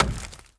OnButtonOk.wav